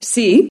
Musiky Bass Free Samples: Voz